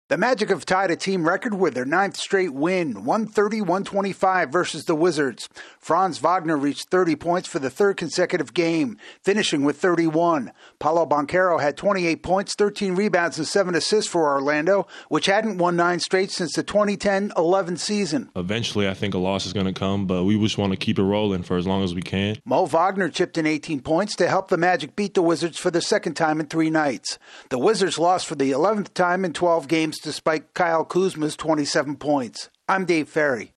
The Magic continue their surprisingly good start. AP correspondent